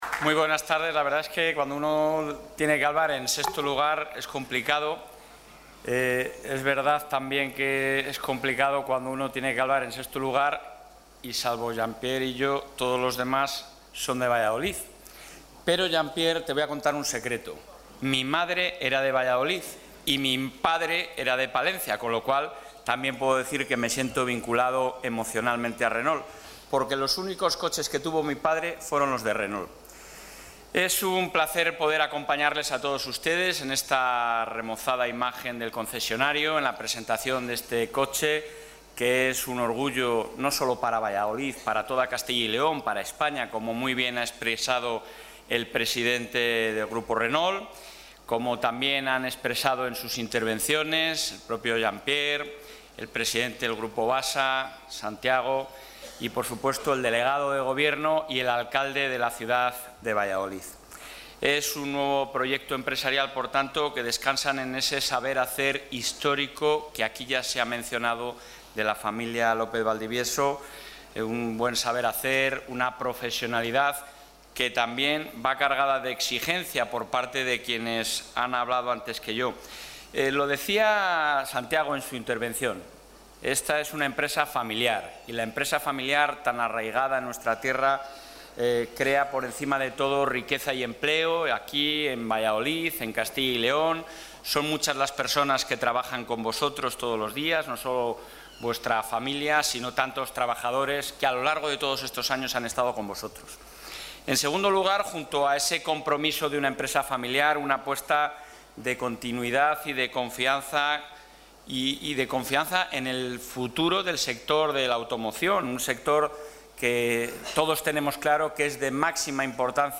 Audio presidente.